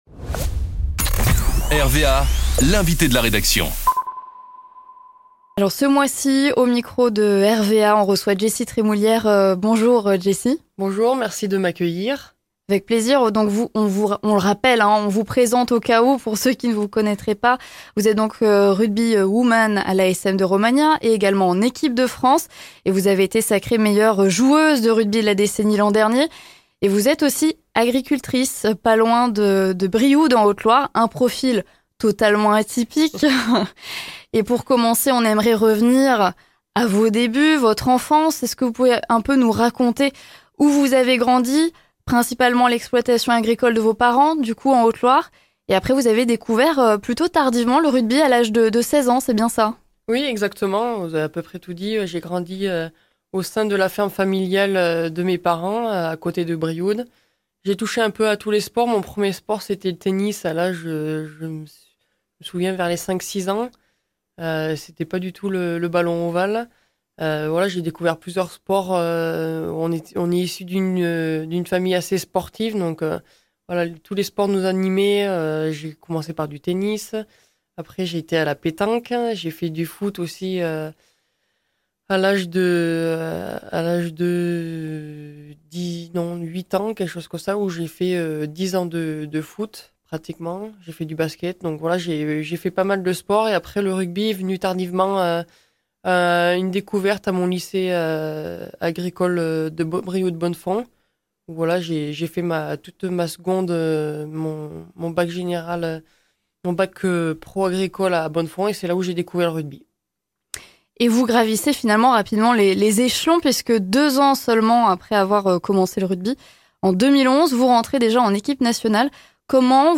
Jessy Trémoulière est notre invité du mois sur RVA. Meilleure joueuse de rugby au monde de la décennie et agricultrice en Haute-Loire, elle se confie sur ce quotidien peu commun et sa carrière presque hors-norme !
Dans notre studio on a reçu Jessy Trémoulière, rugbywoman à l'ASM Romagnat et en équipe de France.